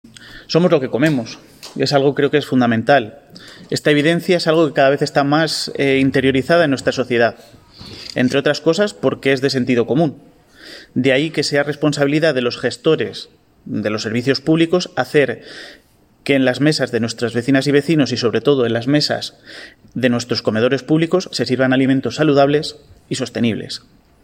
Declaraciones del alcalde, Miguel Óscar Aparicio